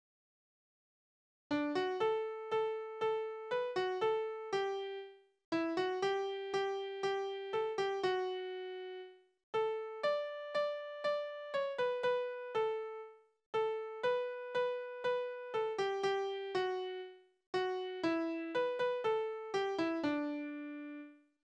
Tonart: D-Dur Taktart: 4/4
Besetzung: vokal